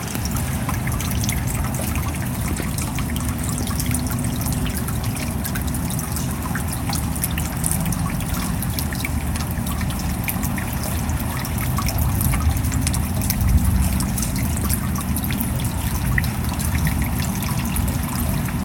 water-drips-1.ogg